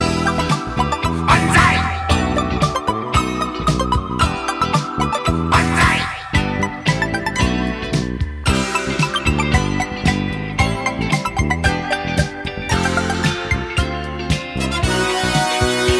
• Качество: 192, Stereo
веселые
саундтреки
80-е
ретро